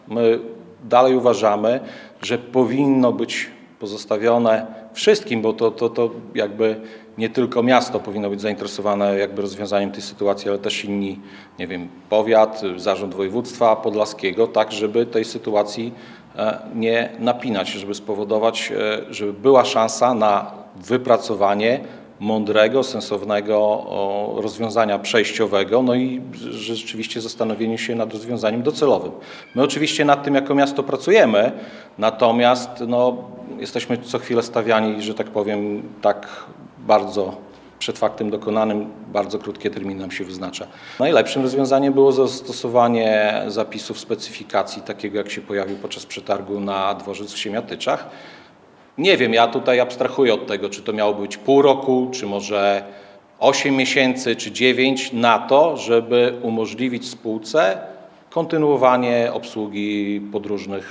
Szczegóły przedstawił Mirosław Karolczuk, zastępca burmistrza Augustowa.
Mirosław-Karolczuk-zastępca-burmistrza-Augustowa-1.mp3